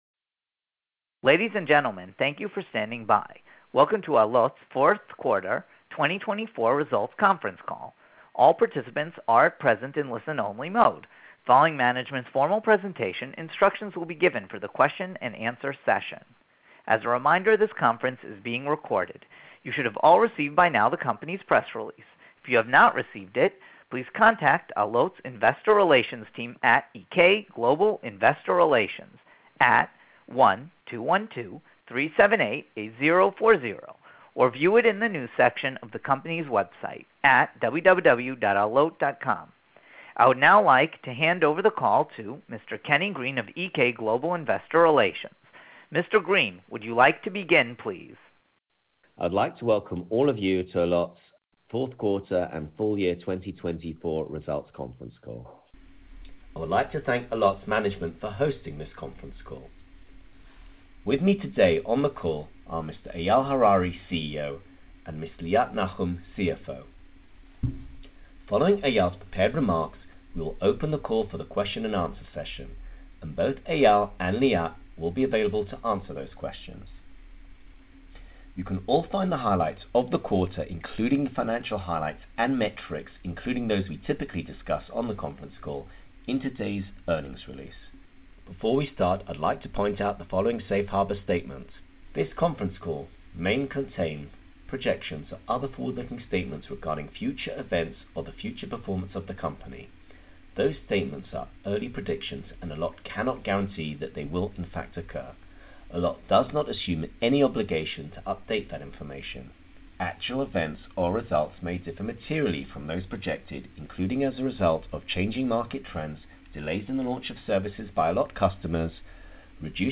Allot Fourth Quarter 2024 Earnings Results Conference Call | Allot Ltd.
Allot Q4 2024 Results Conference Call.mp3